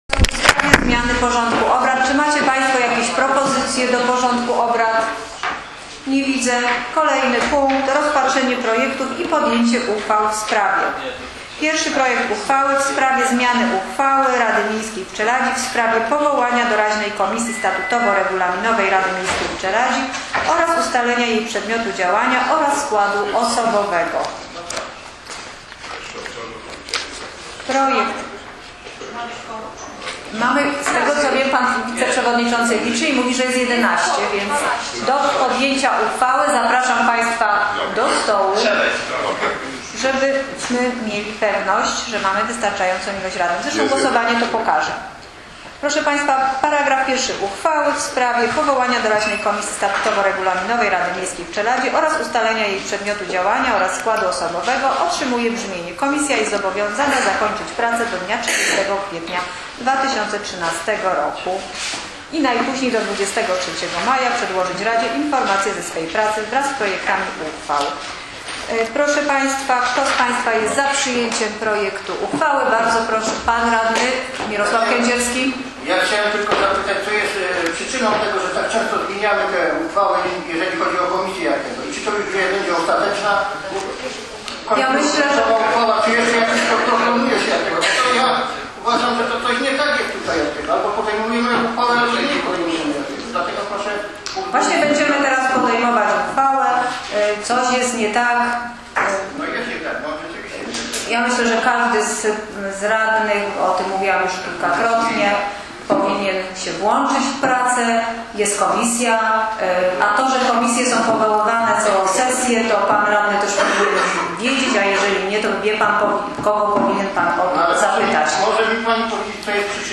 Plik dźwiękowy z Sesji Rady Miejskiej Nr XLVI z dnia 11.03.2013 r.